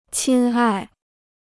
亲爱 (qīn ài): dear; beloved.